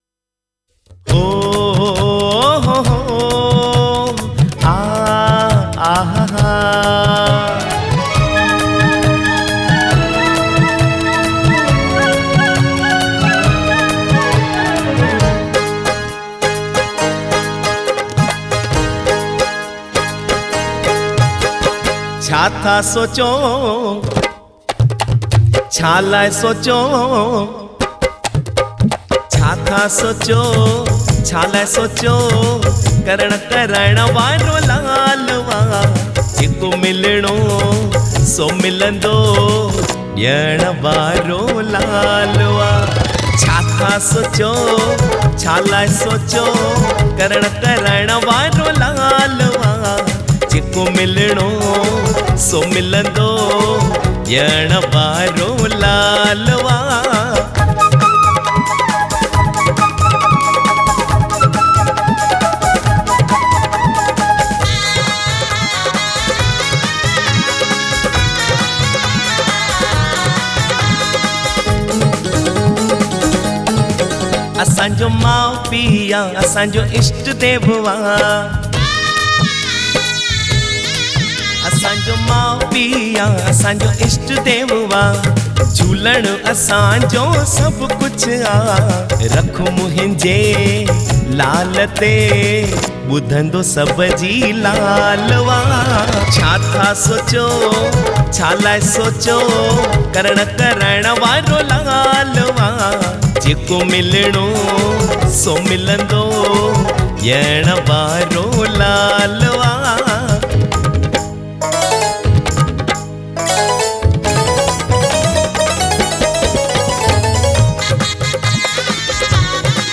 Jhulelal Songs